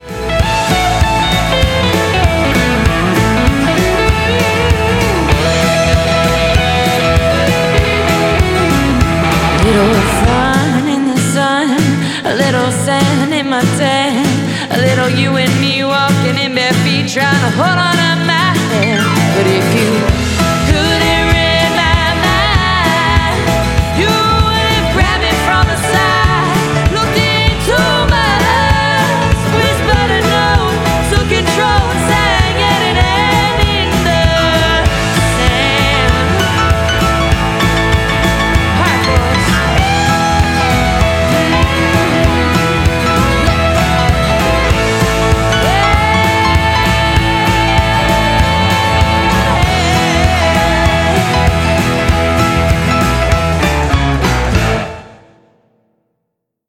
drums, bass
guitar, piano